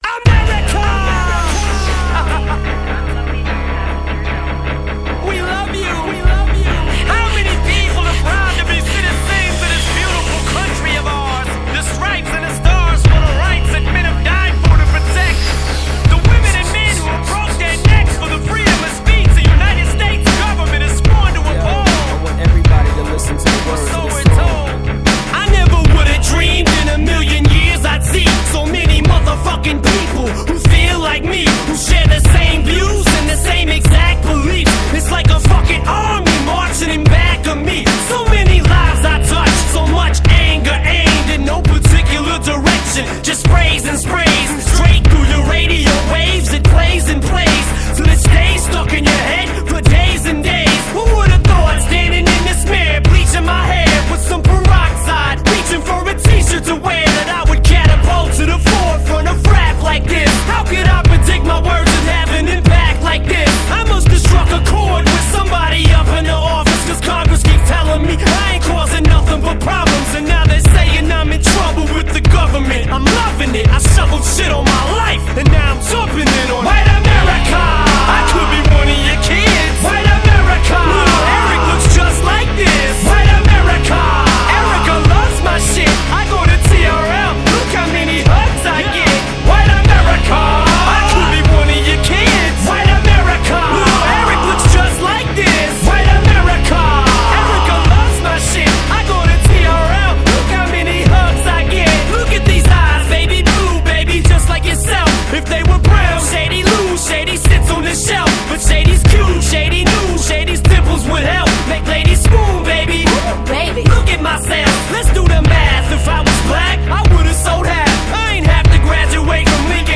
Hip Hop, Rap